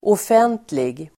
Uttal: [åf'en:tlig]